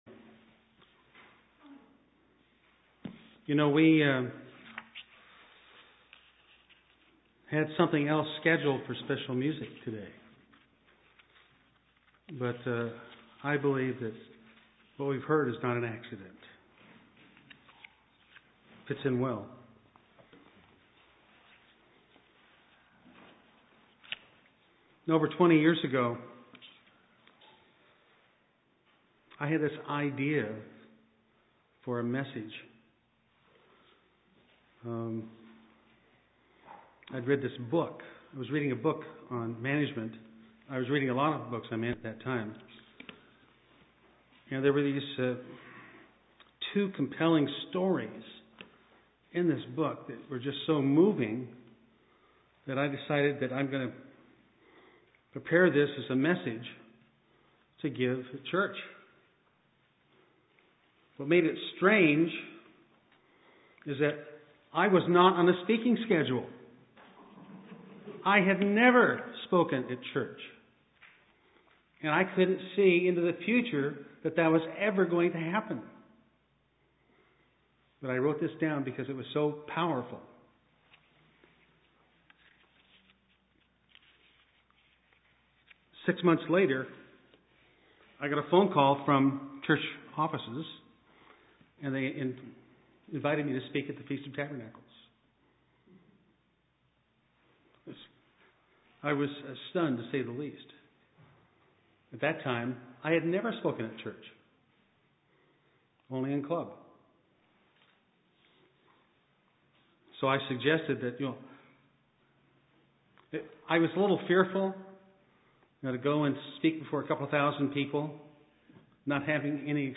Given in Eureka, CA
Print Create a vision of being in the Kingdom of God UCG Sermon Studying the bible?